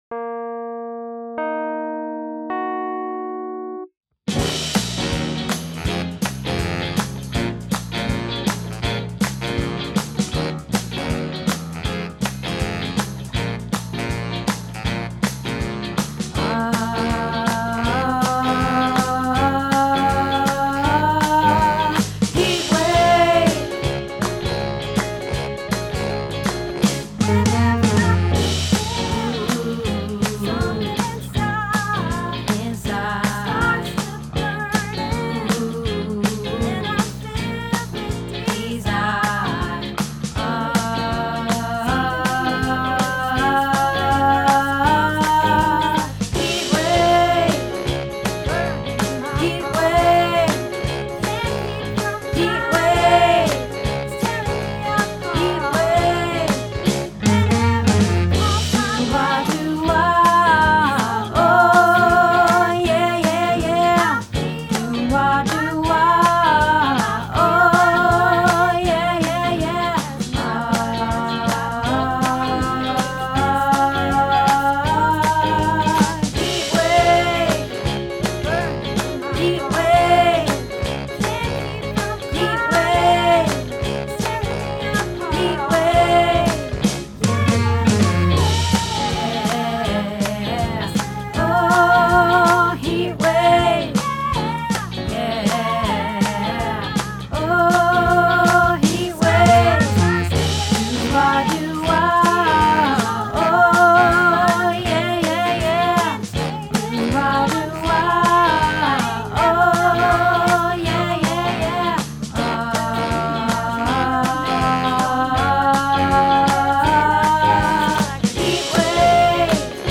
Heatwave - Bass